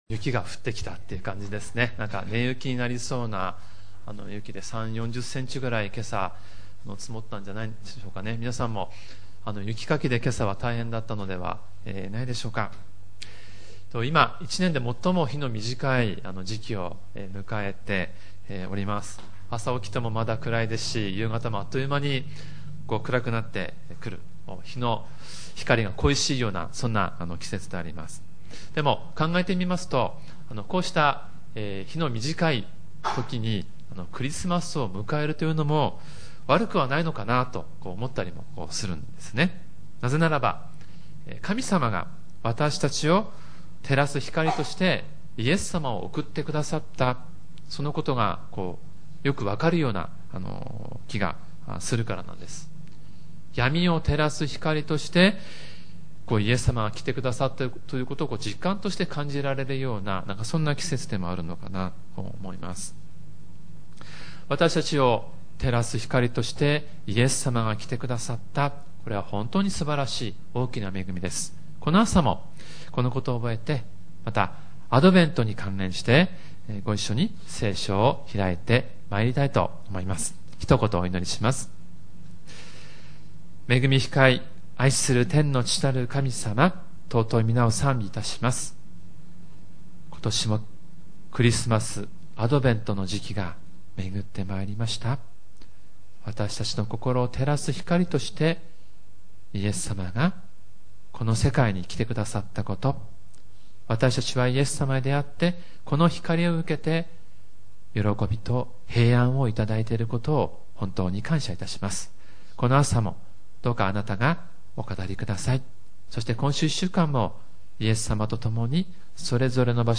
●主日礼拝メッセージ（MP３ファイル、赤文字をクリックするとメッセージが聞けます） イザヤ書9:6-7